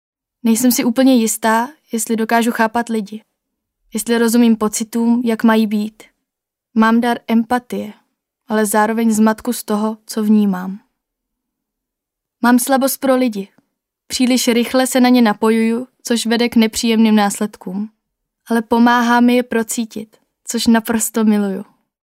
Výpovědi k tématu porozumění: